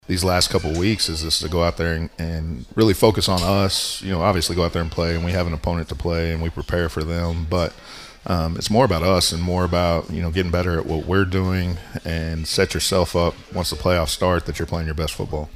The Bruin Football Coaches Show airs every Wednesday evening just after 6:00 from Dink’s Pit BBQ.